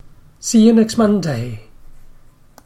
In next Monday  you have a cluster of four consonants in a row [nekst ˈmʌndeɪ] that becomes a bit of a mouthful.
So, English speakers take a short-cut and just drop the /t/.